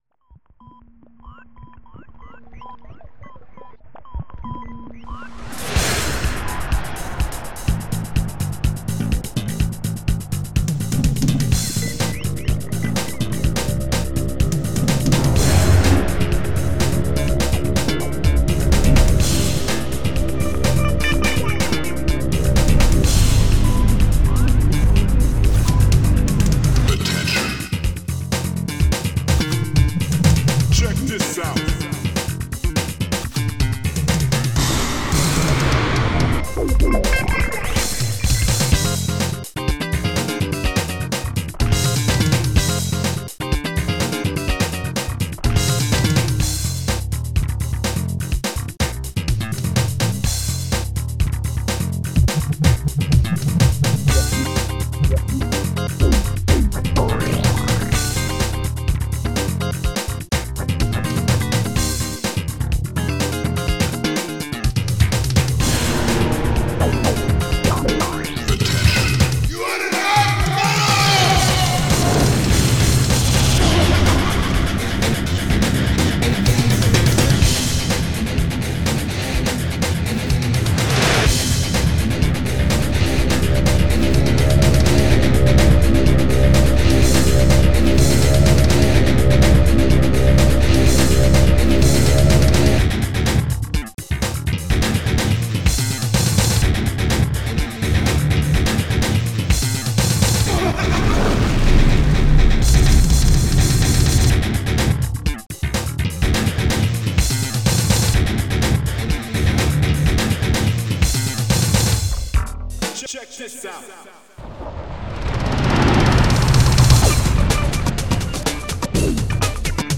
song were the music stops
and the strings began to